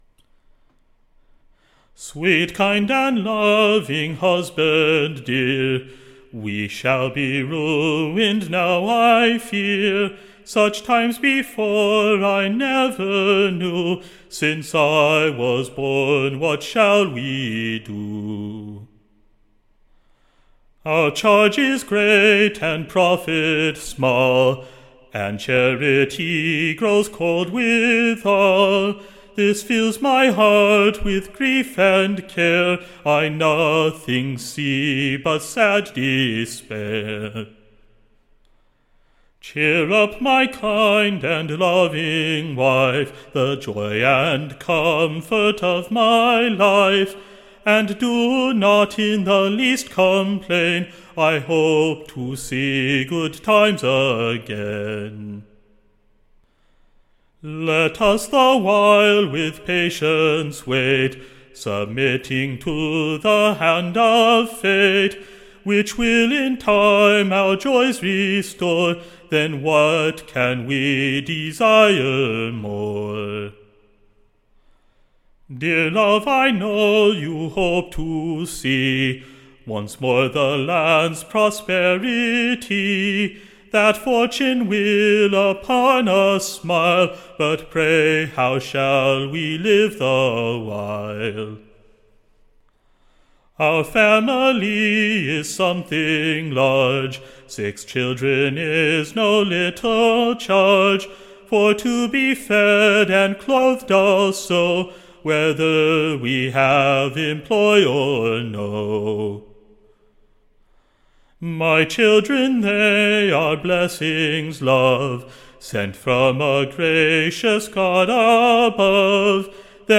Recording Information Ballad Title The Chearful Husband: / Or, The Despairing Wife.